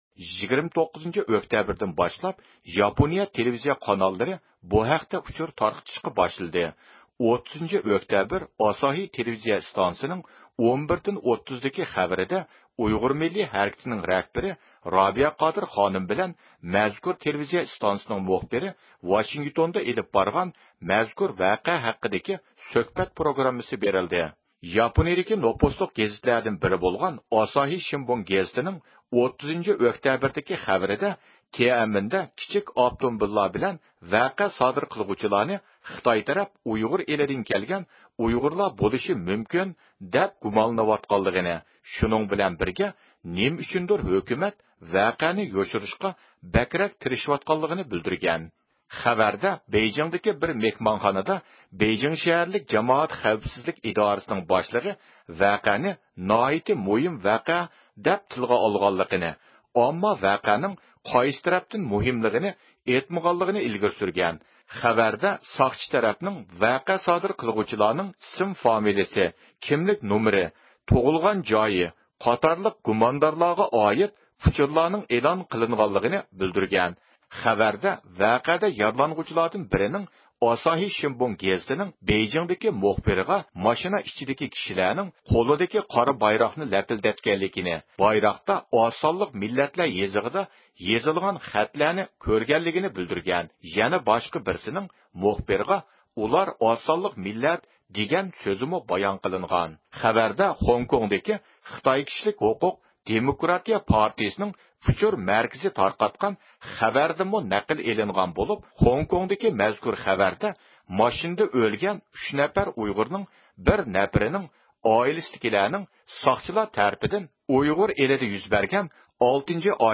تېلېفون سۆھبىتى ئېلىپ باردۇق.